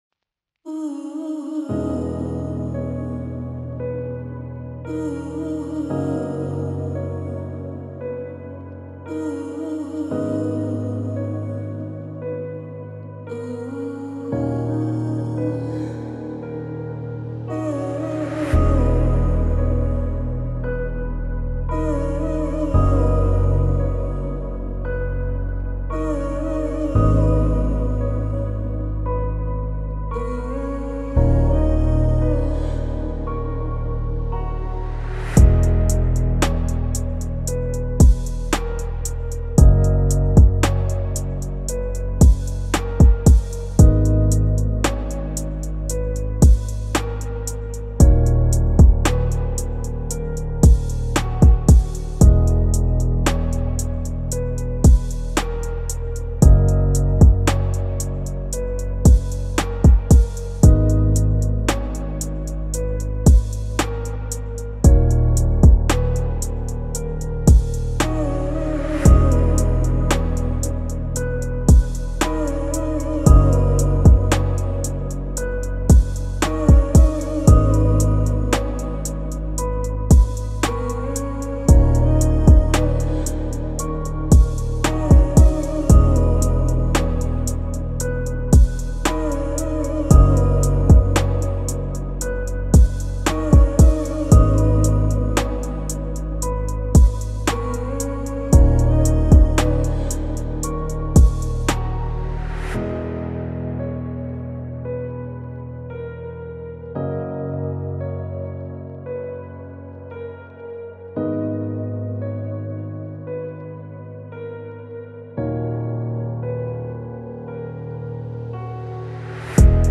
بی‌کلامه اما خیلی حرف داره لعنتی یطوریه
یه غم...